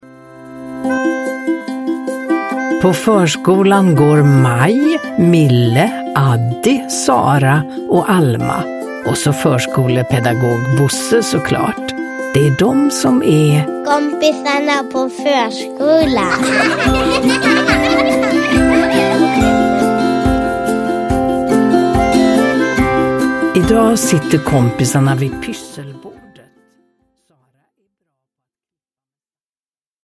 Zarah får plåster – Ljudbok
Uppläsare: Ulla Skoog